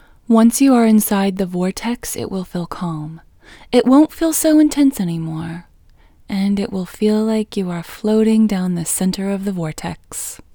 IN – the Second Way – English Female 14